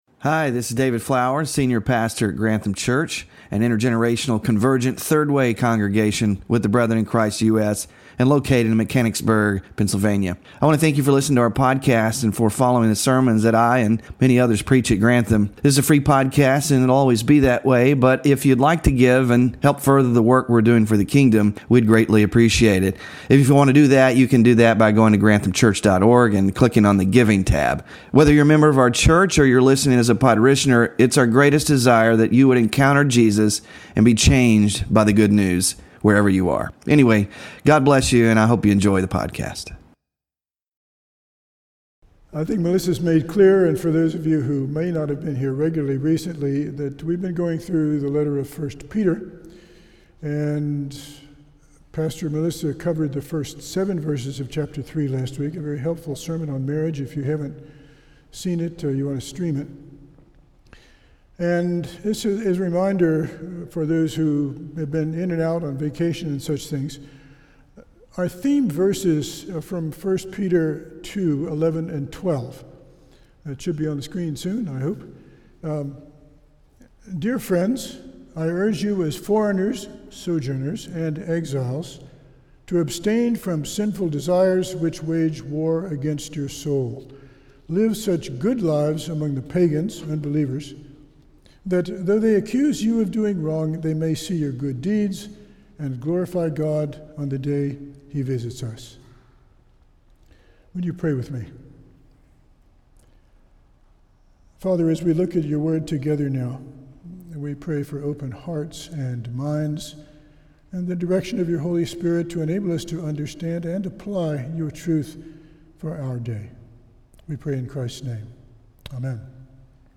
Scripture Reading: Matthew 5:10-12; 2 Timothy 3:12; 1 Peter 3:8–22